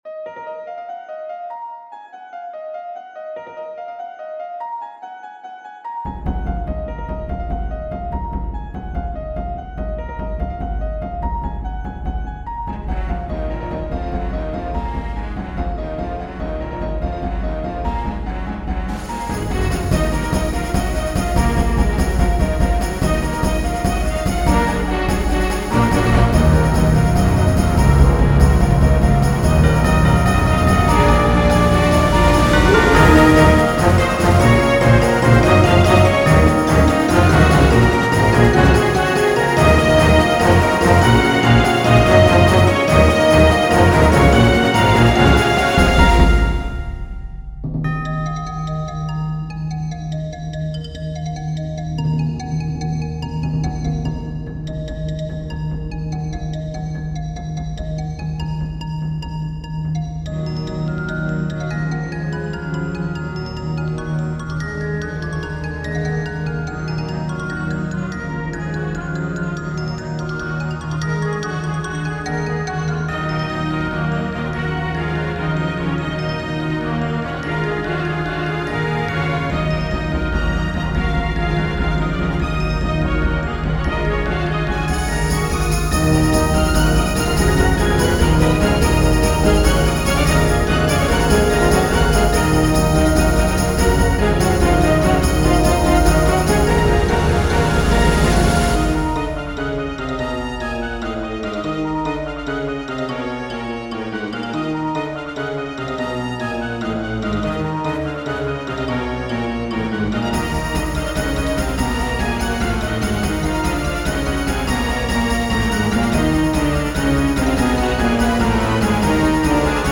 The Wager of the Colors - Orchestral and Large Ensemble - Young Composers Music Forum
I gave the melody more variation, cleaned up scoring errors, and made bland parts more interesting, like the chromatic passage.